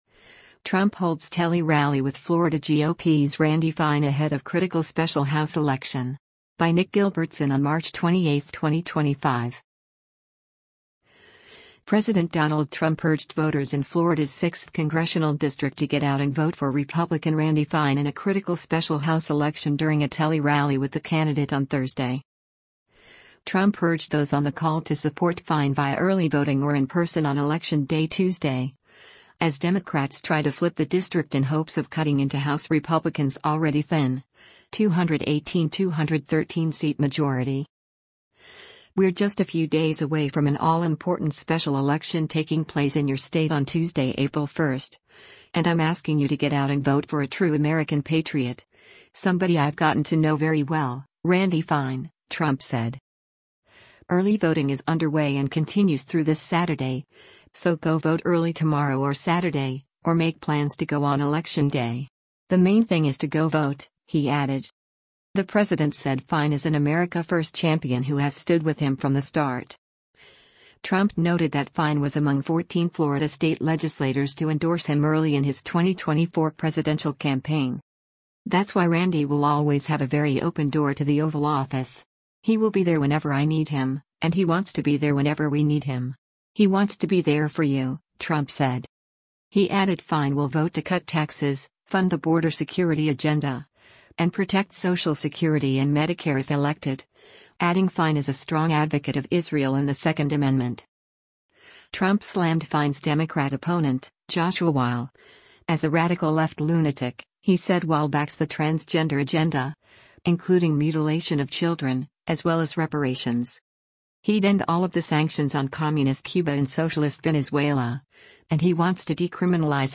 Trump Holds Tele-Rally with Florida GOP's Randy Fine Ahead of Critical Special House Election
President Donald Trump urged voters in Florida’s Sixth Congressional District to get out and vote for Republican Randy Fine in a critical special House election during a tele-rally with the candidate on Thursday.